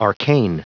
Prononciation du mot arcane en anglais (fichier audio)
Prononciation du mot : arcane